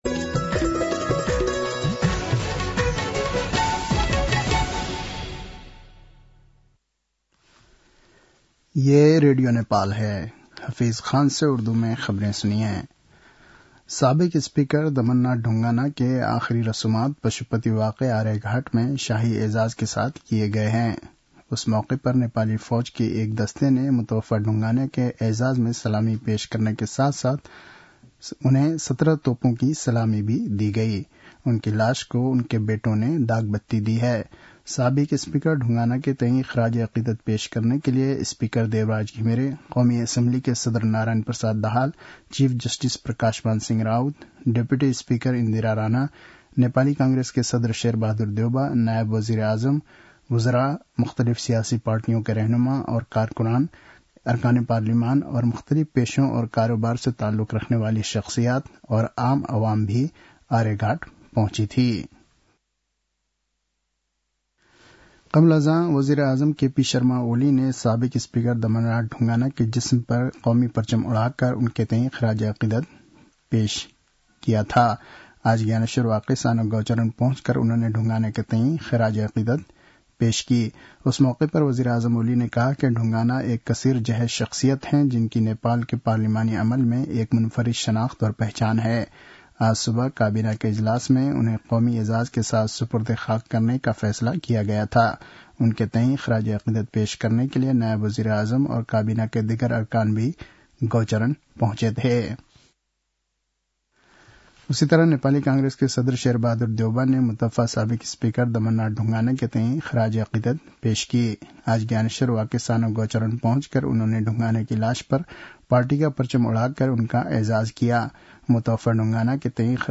उर्दु भाषामा समाचार : ४ मंसिर , २०८१
Urdu-news-8-02.mp3